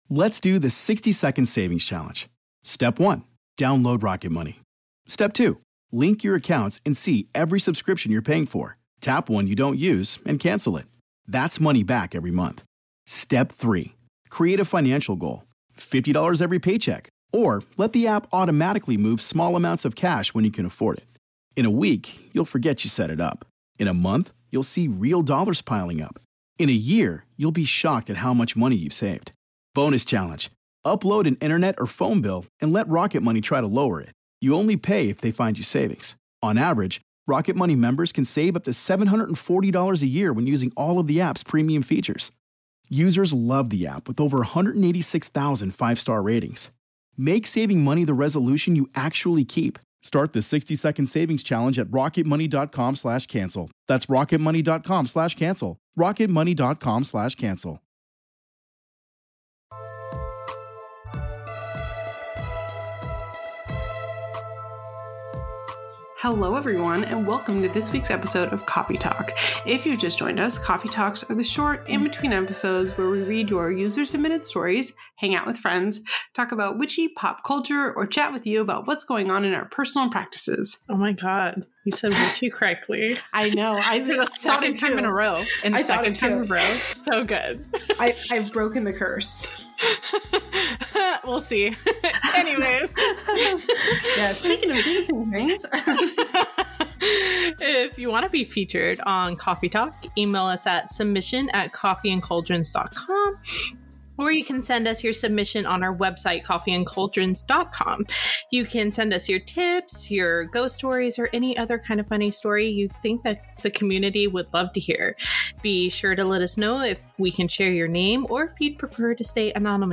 This week we just have a casual chat!